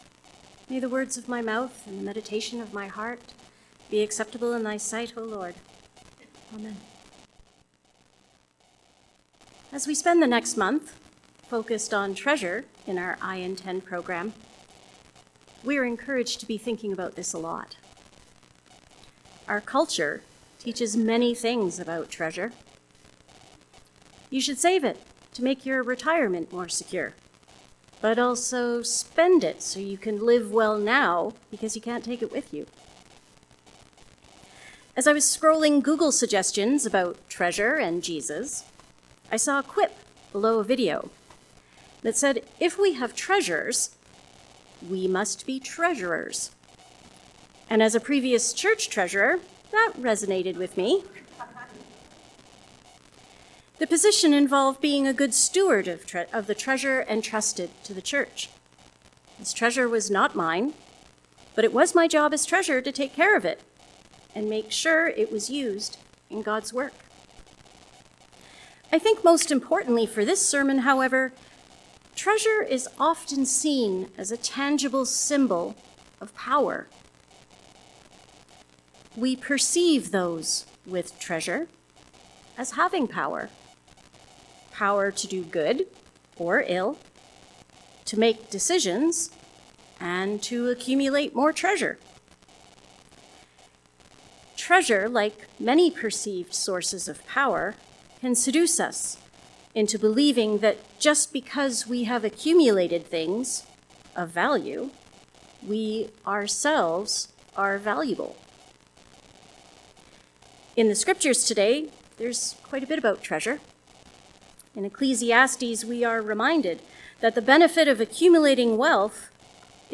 Sermon on Treasure, based on Ecclesiastes 5:10-20 Ps 62:5-12 1 Tim 6:17-19 Matthew 6:19-24